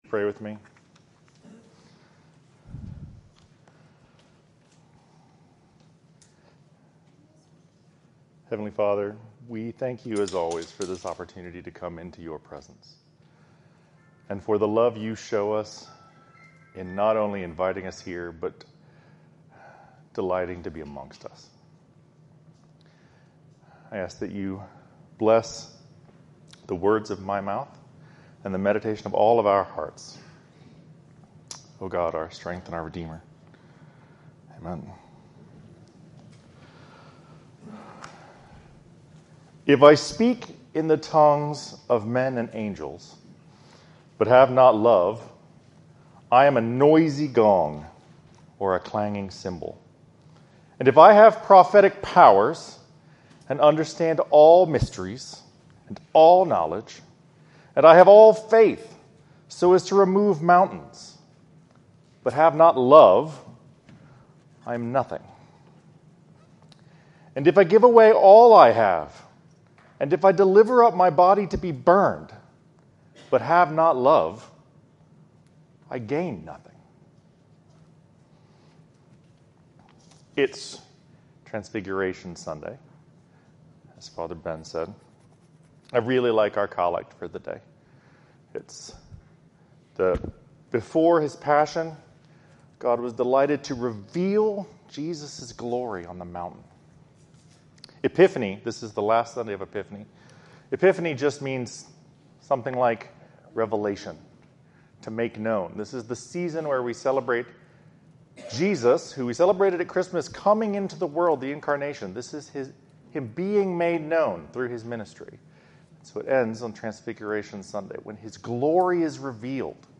In this sermon on Transfiguration Sunday